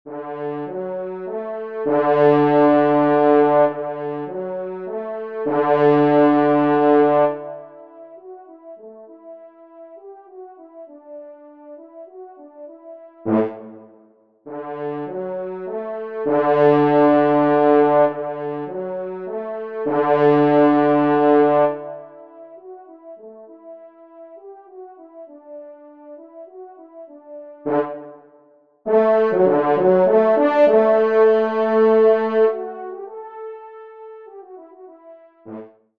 Genre : Divertissement pour Trompes ou Cors
Pupitre 4° Cor